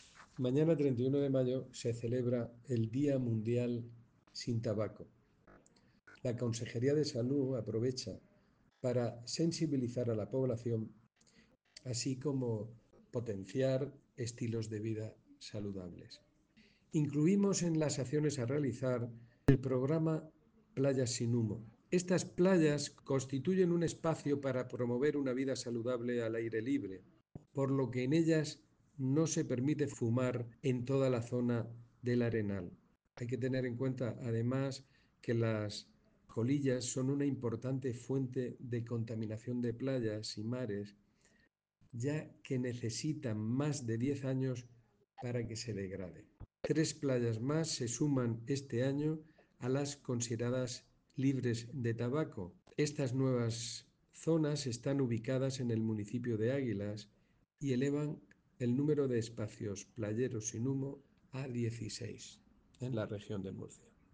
Sonido/ Declaraciones del director general de Salud Pública y Adicciones, José Jesús Guillén, sobre las playas sin humo de la Región.